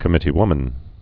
(kə-mĭtē-wmən)